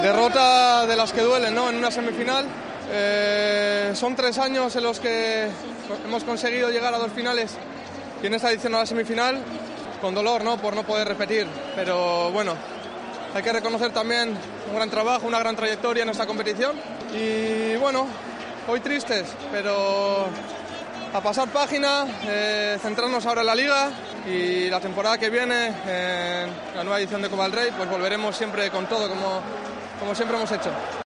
AUDIO: El capitán del Athletic habló de la eliminación en semifinales de Copa ante el Valencia.